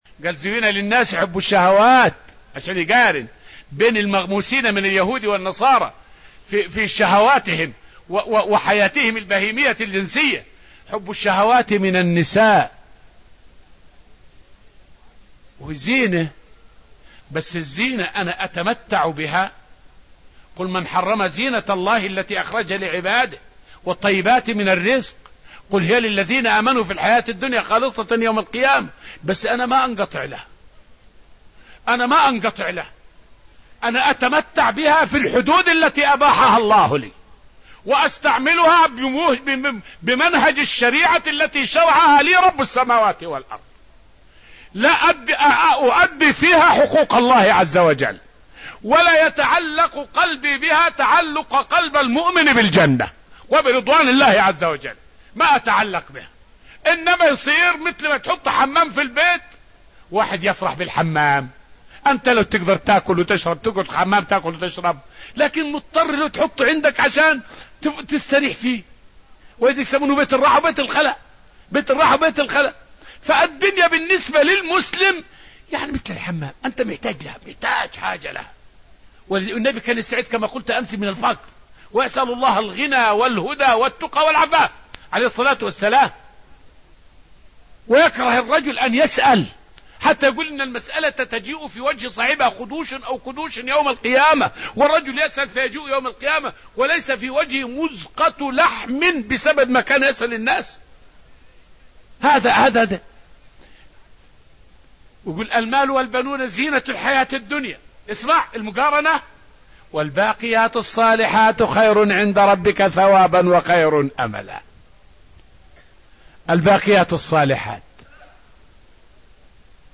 فائدة من الدرس الثالث من دروس تفسير سورة آل عمران والتي ألقيت في المسجد النبوي الشريف حول تفسير آية {زين للناس حب الشهوات}.